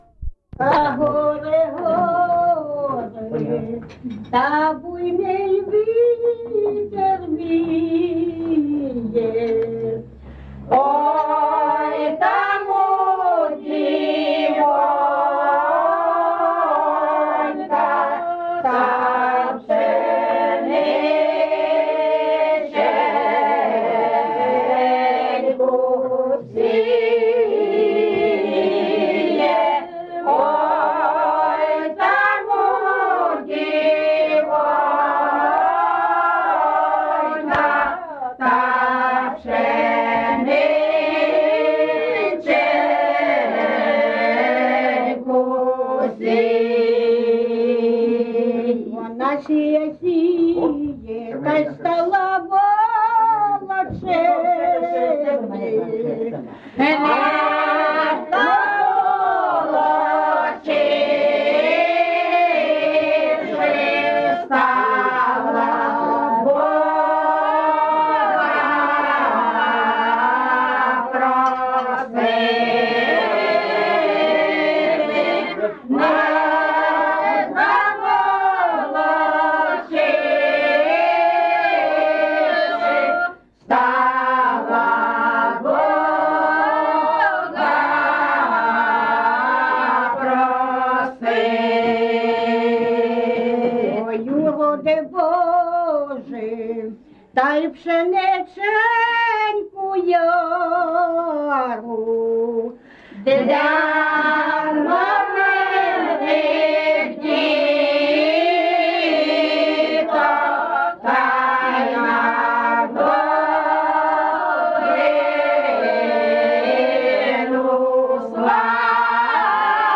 ЖанрПісні з особистого та родинного життя
Місце записус. Шарівка, Валківський район, Харківська обл., Україна, Слобожанщина